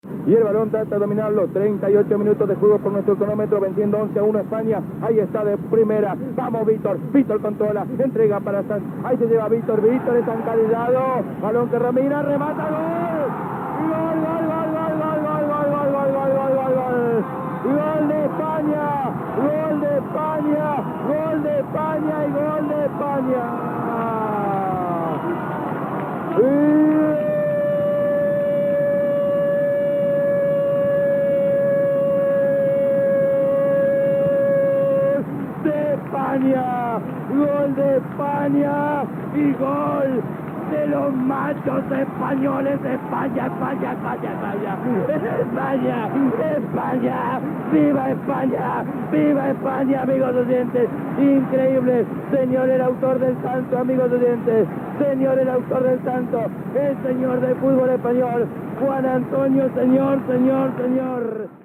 Minut i resultat, narració del gol de Señor en el partit de futbol masculí Espanya Malta que suposava el 12 a 1 i la classificació d'Espanya per a l'Eurocopa de França
Esportiu